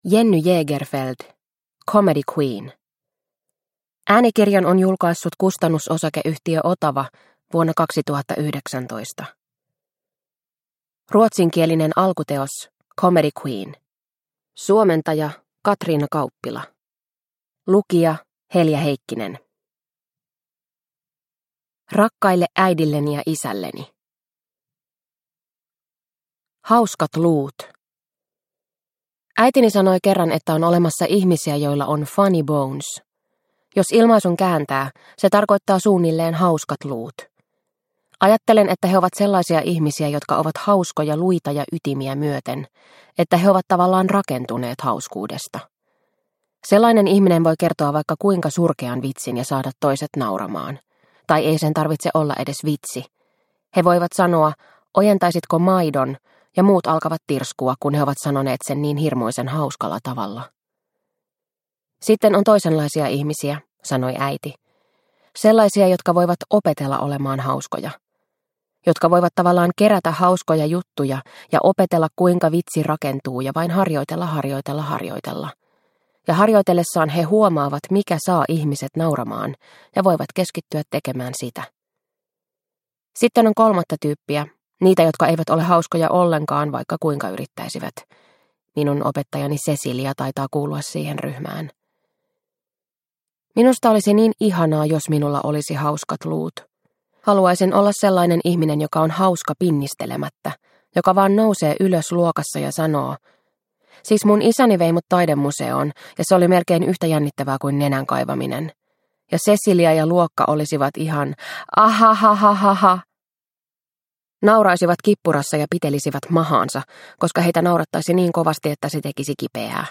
Comedy Queen – Ljudbok – Laddas ner